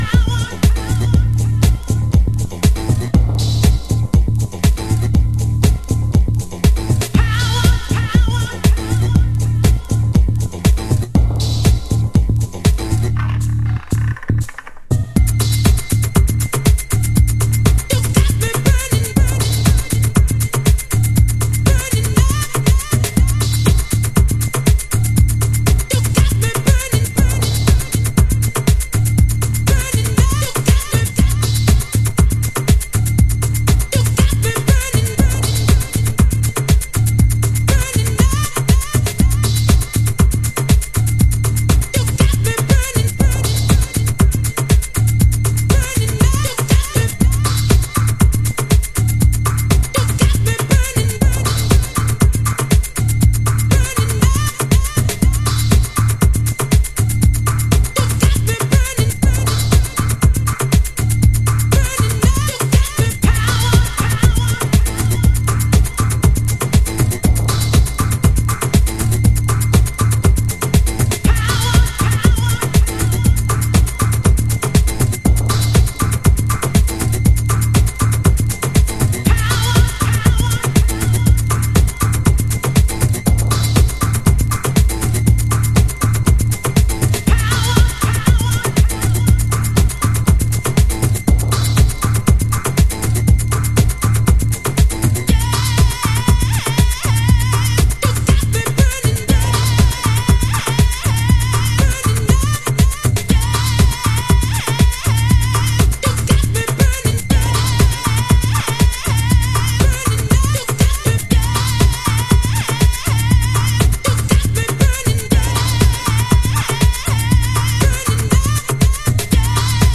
Early House / 90's Techno
粘着ベースシンセサイザーとブギーグルーヴ、ストレートなヴォイスに思わず熱くなる名曲。